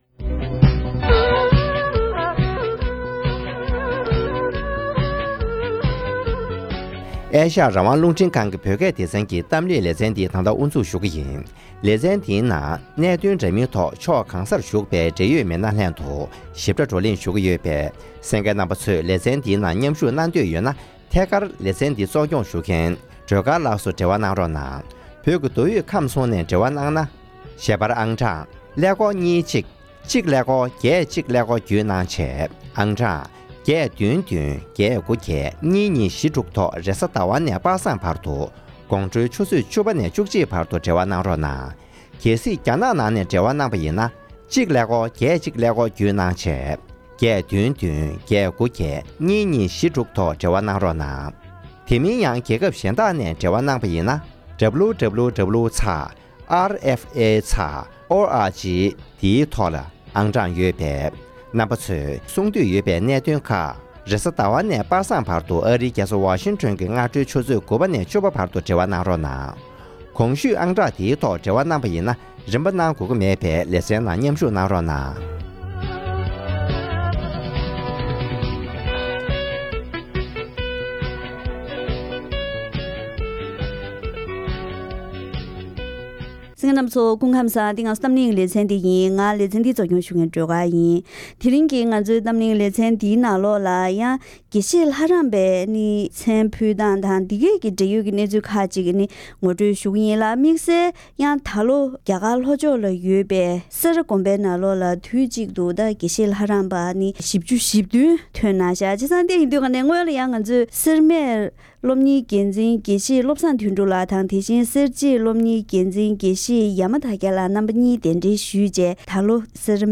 ༄༅། །དེ་རིང་གི་གཏམ་གླེང་ལེ་ཚན་ནང་། འདི་ལོ་སེ་ར་དགོན་པ་ནས་དགེ་བཤེས་ལྷ་རམས་པའི་དམ་བཅའ་འཇོག་མཁན་ལོ་རྒྱུས་ཐོག་གྲངས་མང་ཤོས་འདི་ཐོན་ཡོད་པ་རེད། བཙན་བྱོལ་ནང་ཡོད་པའི་སེ་འབྲས་དགའ་གསུམ་ནང་སློབ་གཉེར་གནང་ཕྱོགས་དང་ལྷ་རམས་པའི་དམ་བཅའ་འཇོག་ཚུལ་སྐོར་ལ་འབྲེལ་ཡོད་དང་ལྷན་དུ་བཀའ་མོལ་ཞུས་པ་ཞིག་གསན་རོགས་གནང་།།